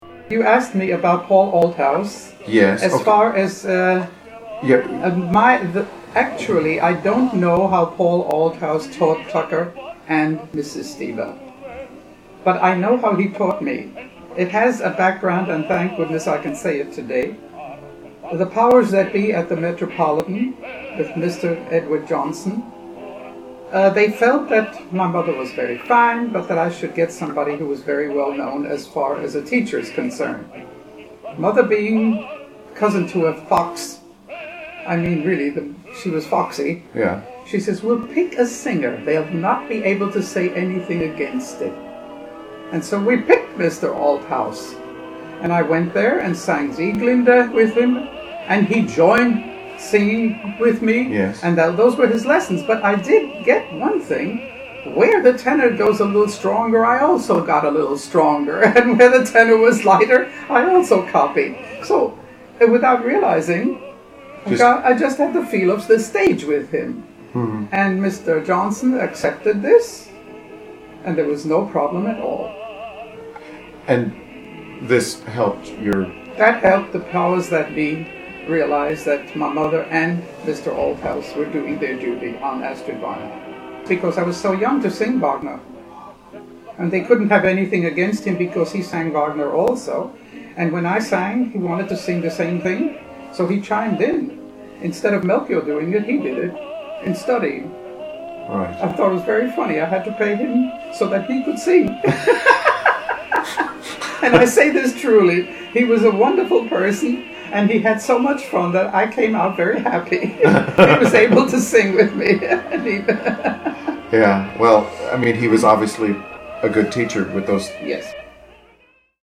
I have included some of her singing and also chose to include two narratives - her letter of introduction to the Met and the saga of saving a Met Götterdämmerung, which are read by me.